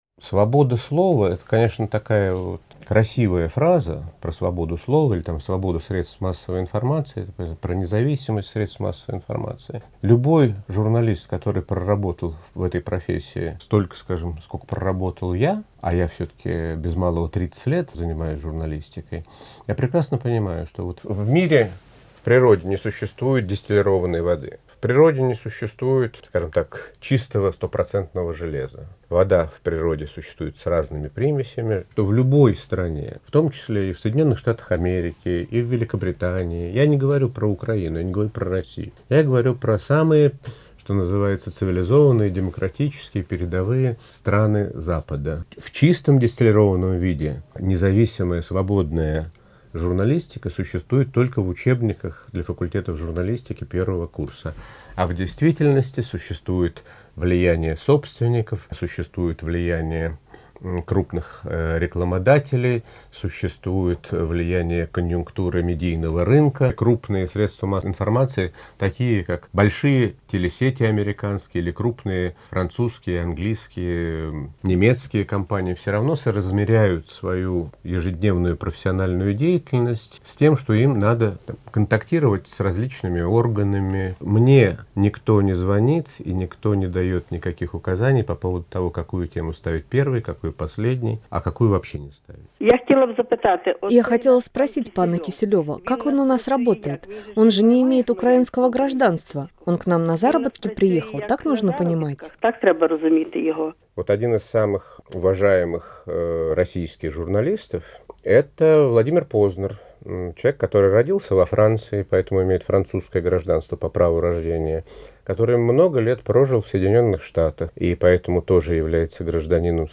Российский журналист Евгений Киселев, в последние годы работающий в Киеве, обратился к президенту Украины Виктору Януковичу с просьбой о предоставлении украинского гражданства. В интервью РС Киселев объяснил почему